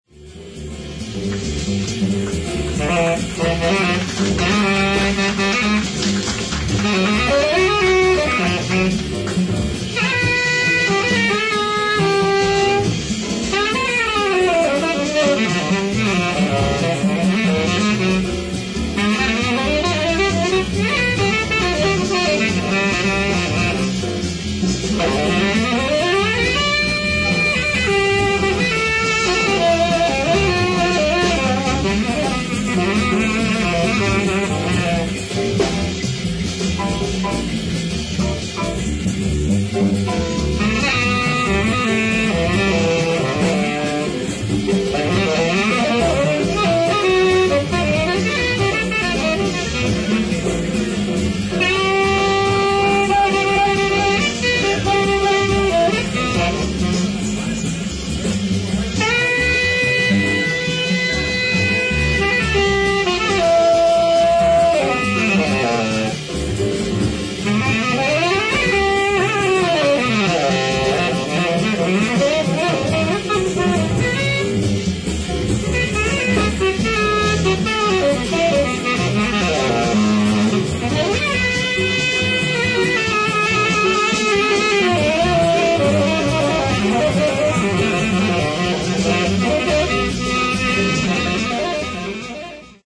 ライブ・アット・ヴィレッジ・バンガード、ニューヨーク 04/14/1974